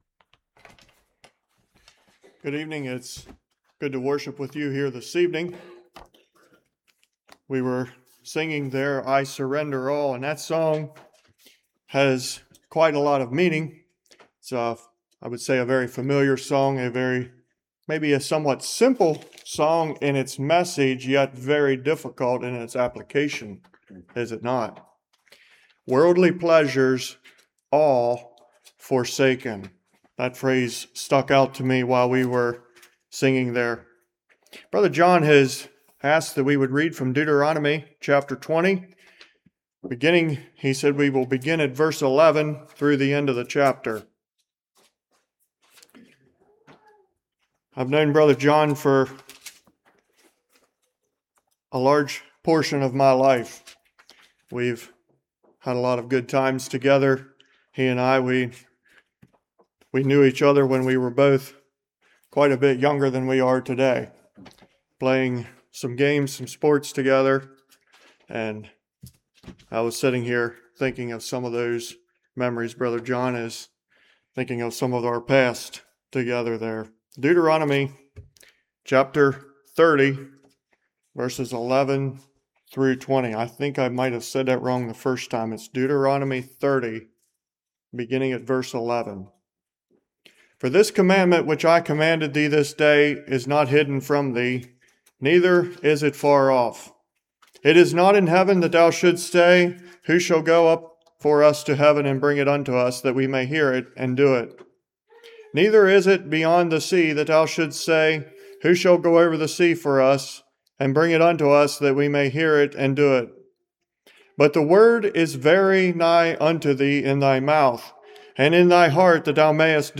Deuteronomy 30:11-20 Service Type: Revival How often do we hold on to material things rather than what is of eternal value?